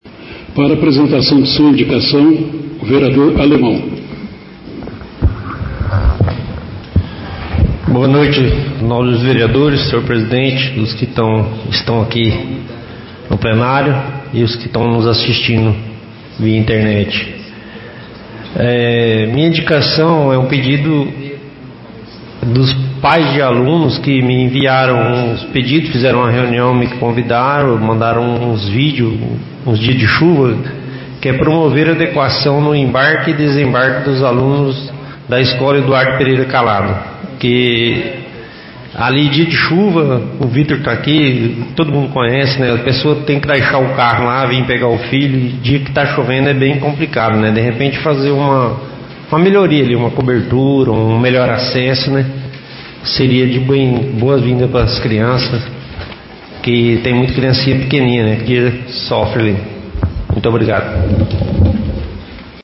Em  sessão realizada na noite da ultima segunda-feira (06), o vereador Alemão solicitou através da Indicação 07/2023, que seja encaminhada ao Prefeito Guga providências no sentido de promover adequação do embarque e desembarque dos Alunos da Escola Municipal Eduardo Pereira Calado.